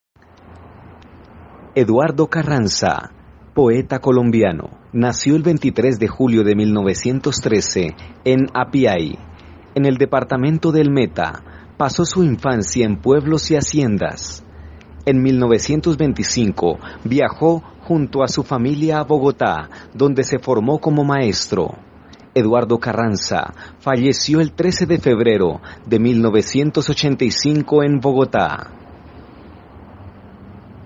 A kontinuasel un tour a través de la músita y las imágenes de los lugares más hermosos de ésta ciudad y del departasierto del *META como lo son: La Sierra de la Macarena, el Ombligo de Colombia en Puerto López, Caño Cristal el Río del Arcoiris,Rio Manacacias, Parque los Ocarros, Malokas, Bocas Puerto Gaitan, Salto de Santo Domingo Menegua, Parque los Fundadores…